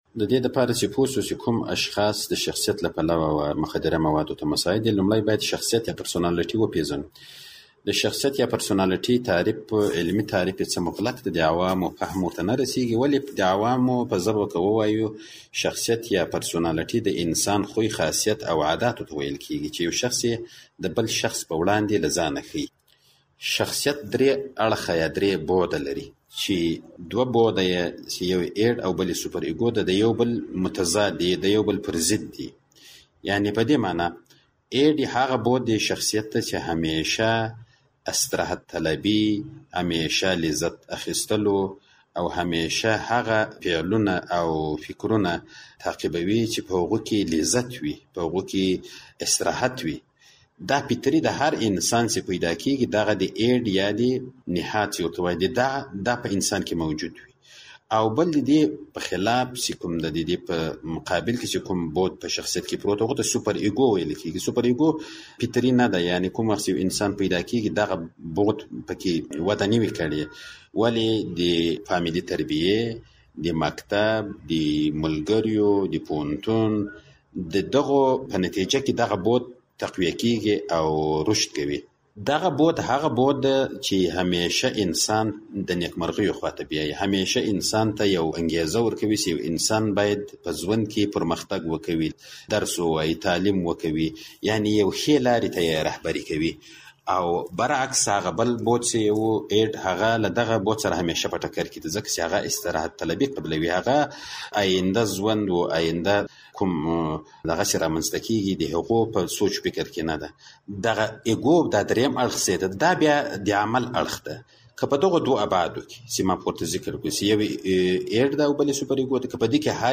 مرکه